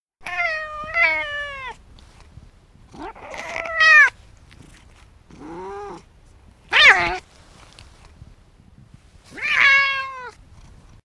cat-loud-meows.mp3